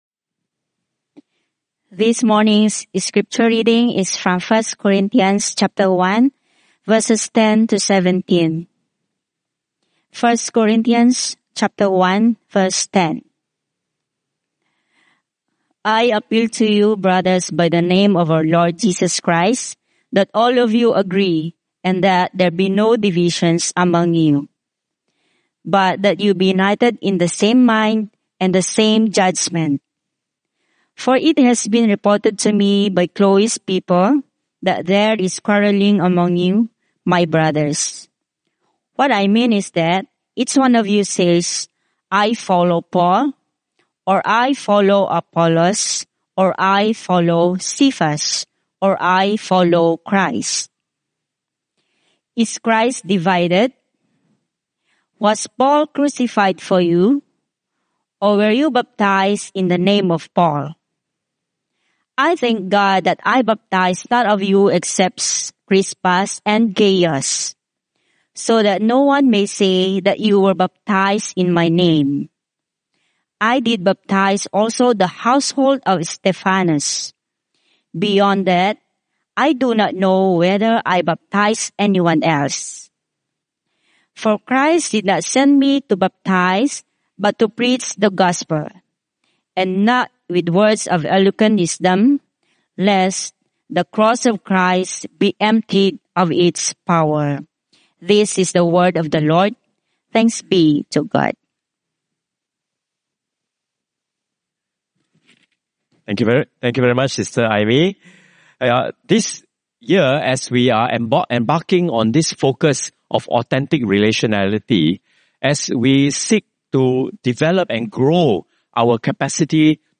Sermon Archives - St.John's-St.Margaret's Church